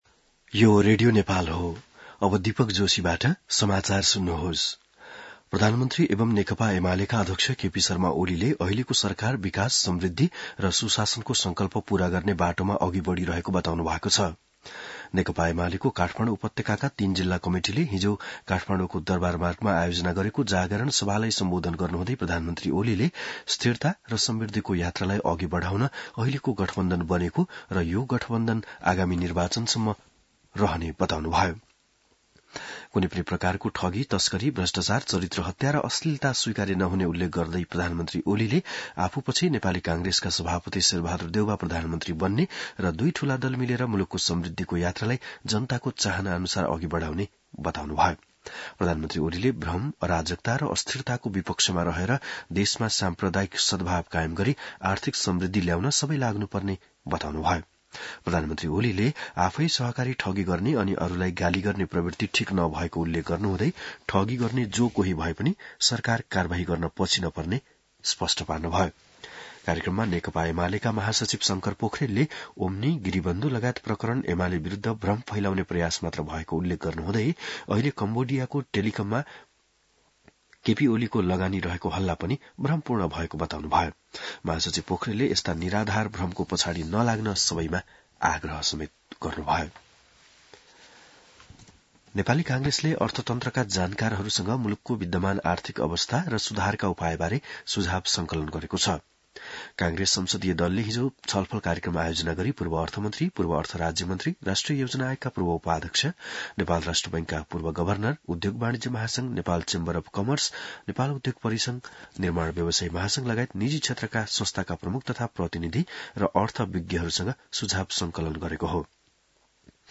बिहान १० बजेको नेपाली समाचार : ९ मंसिर , २०८१